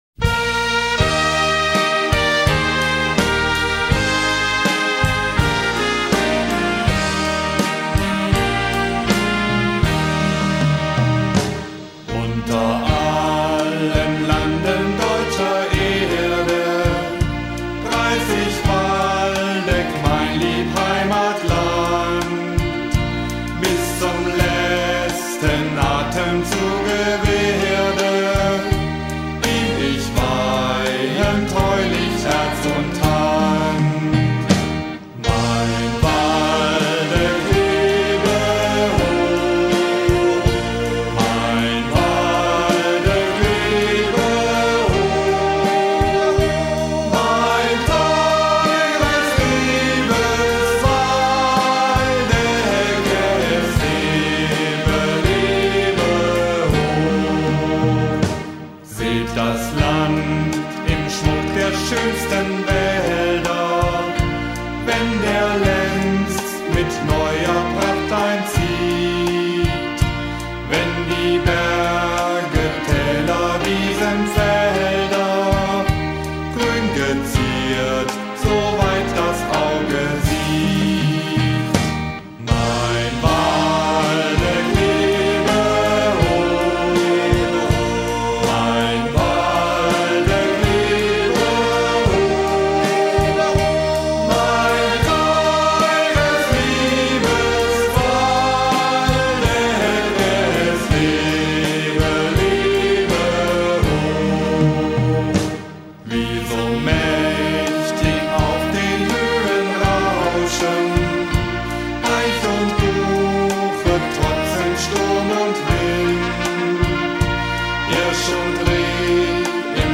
Waldecker Lied mit Orchesterbegleitung